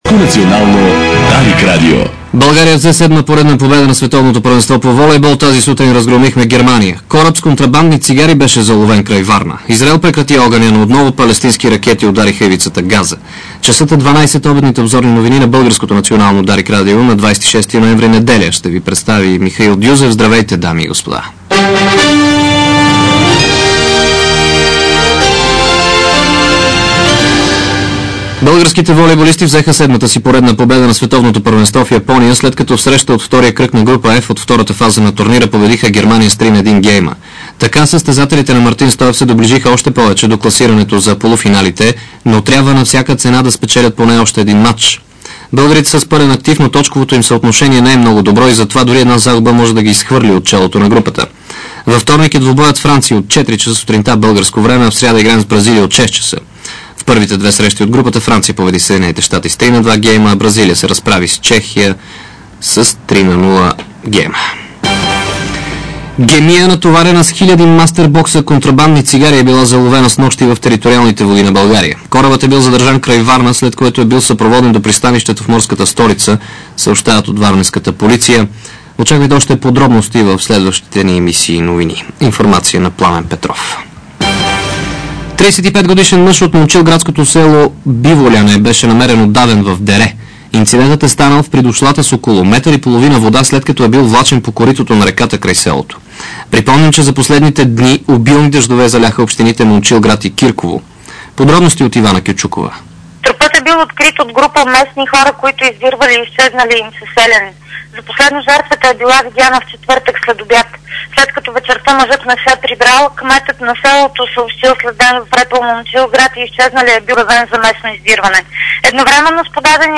Обедна информационна емисия - 26.11.2006